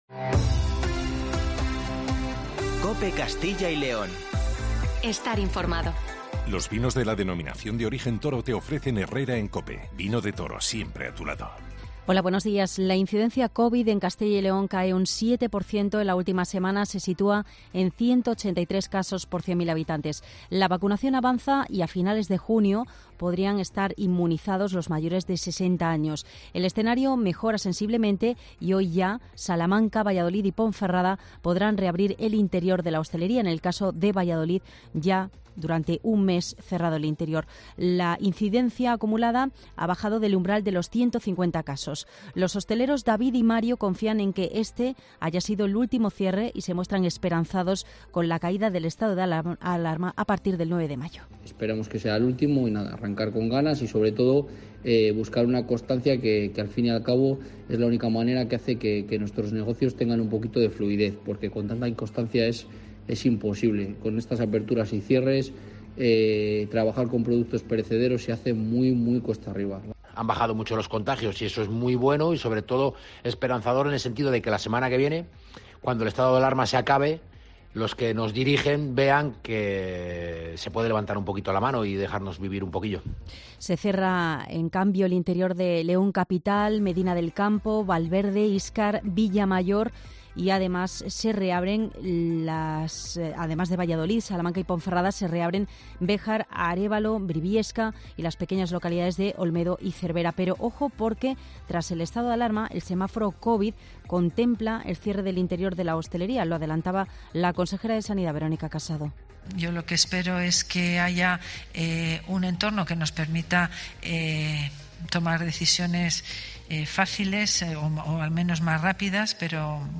Informativo Matinal 7:50